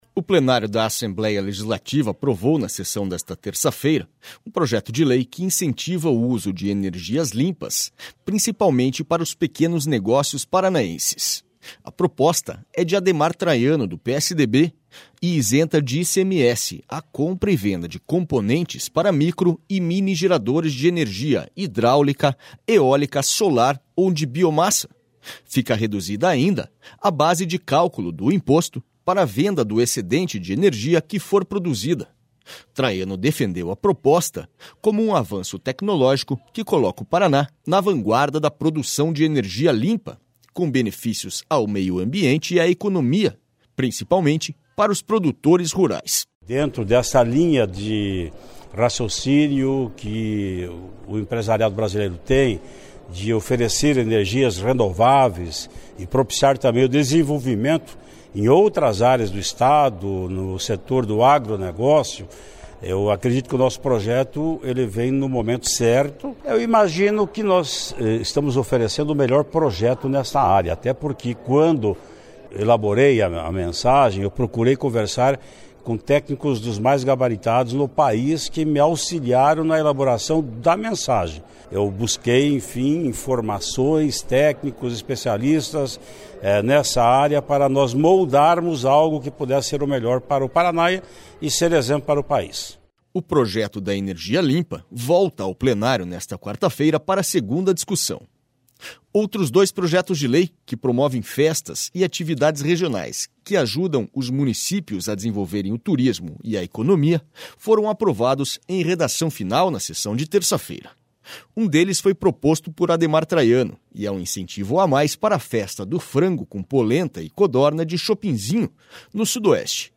SONORA ADEMAR TRAIANO